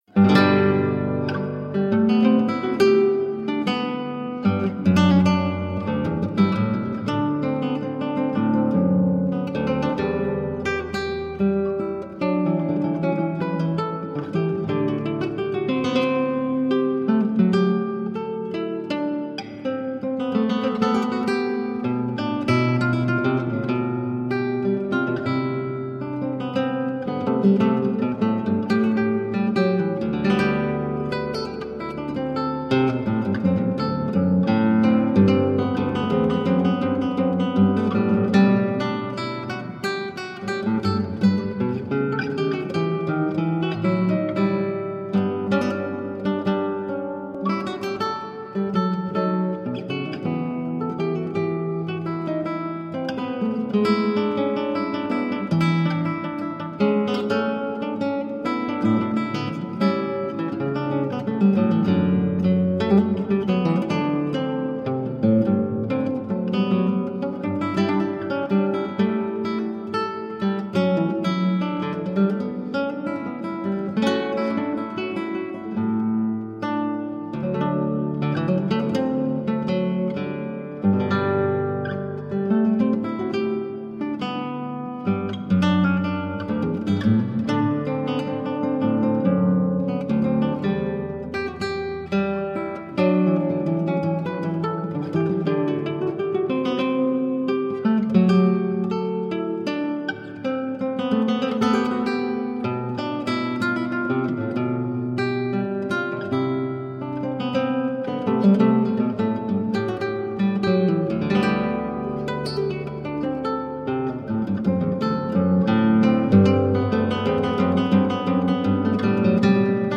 Elegant classical guitar